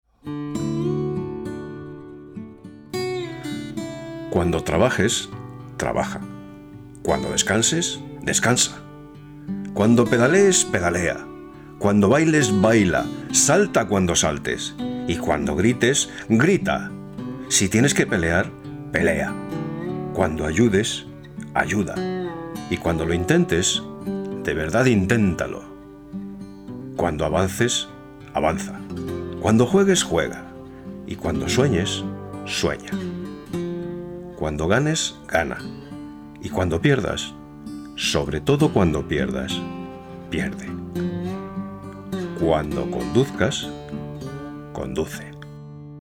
A mature and authoritative, reliable and cordial voice.
Cuña publicitaria (Campaña BMW).
Castelanian
Middle Aged